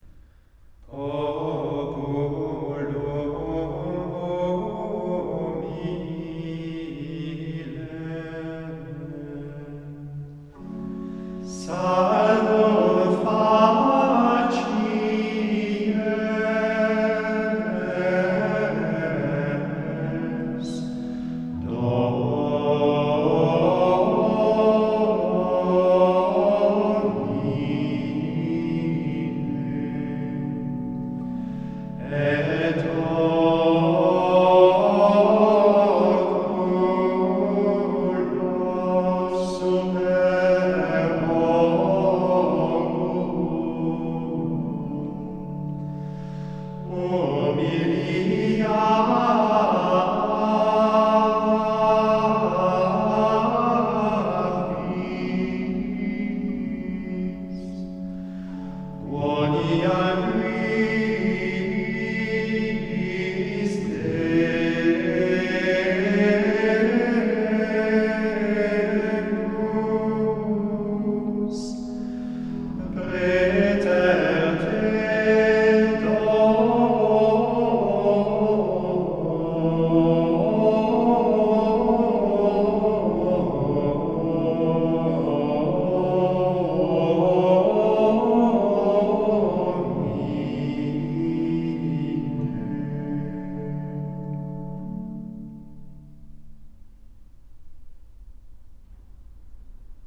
04-offertoire-7.mp3